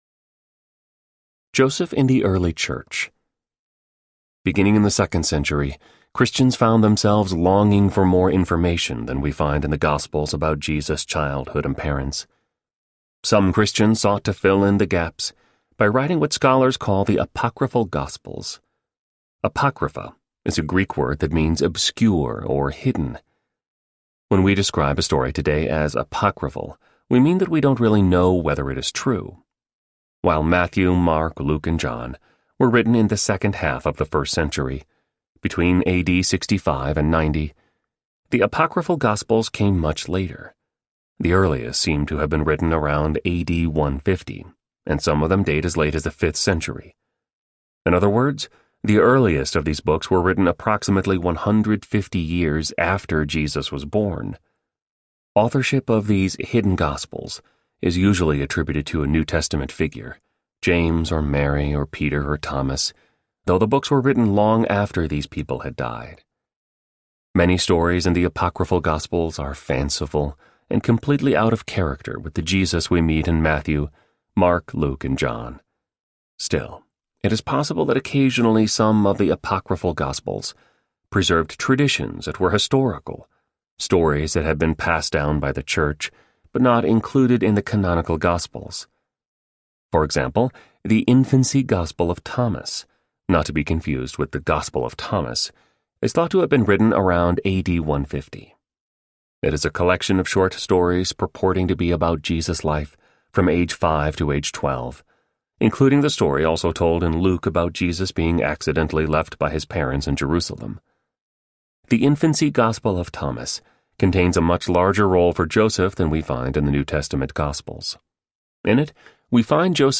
Faithful Audiobook
Narrator
2.6 Hrs. – Unabridged